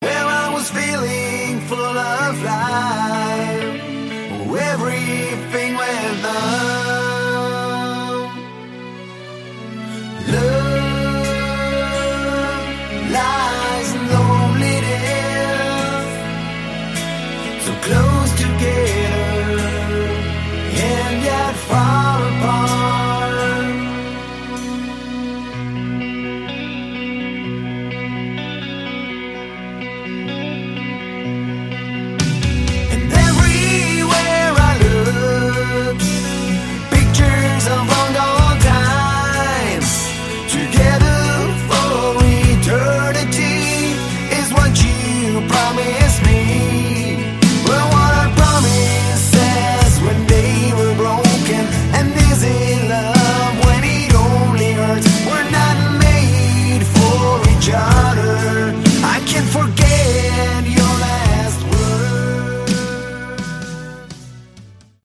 Category: Hard Rock
vocals, guitar
lead guitar